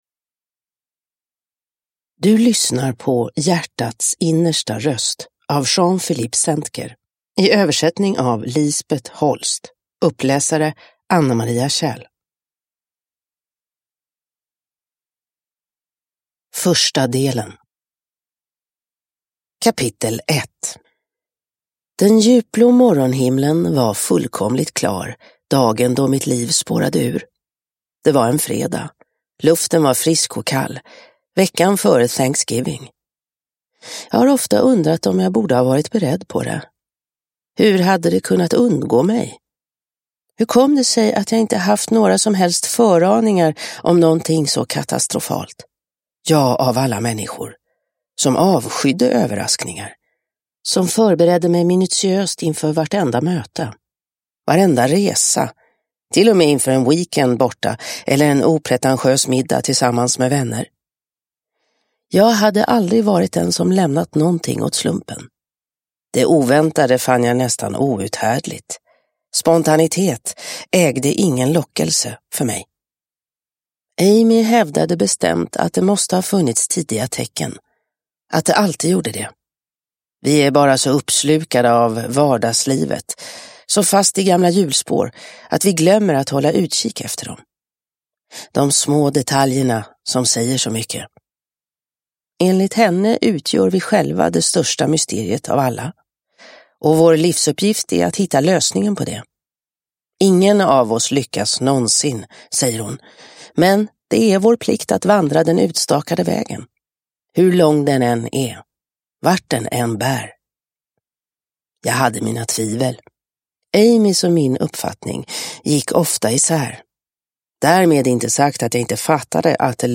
Hjärtats innersta röst – Ljudbok – Laddas ner